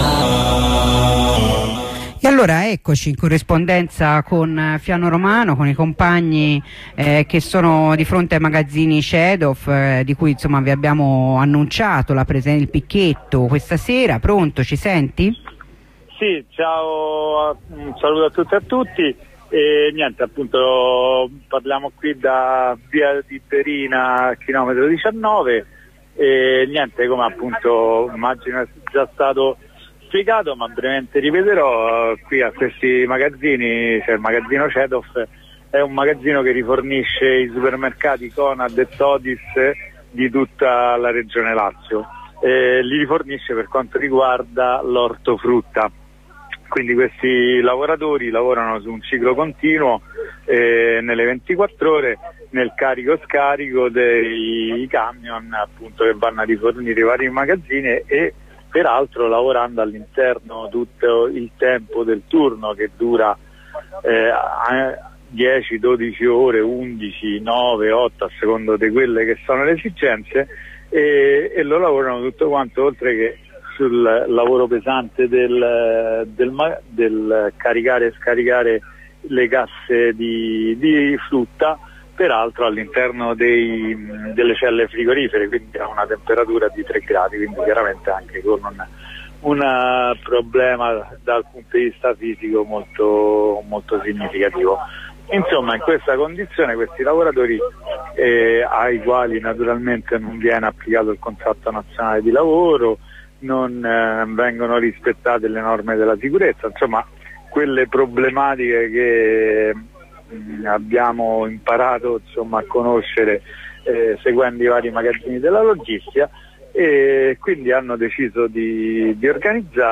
La corrispondenza dal picchetto davanti ai cancelli dei magazzini della Cedof a Fiano Romano. I facchini che erano stati sospesi a tempo indeterminato per uno sciopero di due giorni per il rispetto del CCNL, sostenuti da un numeroso e determinato picchetto, sono rientrati sul loro posto di lavoro.